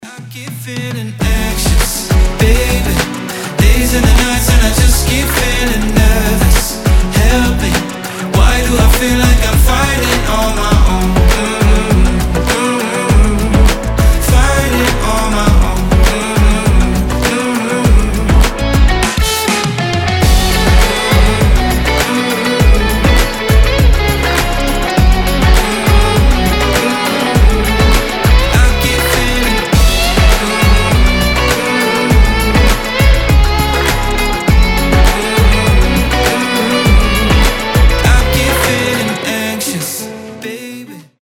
красивый мужской голос
RnB
alternative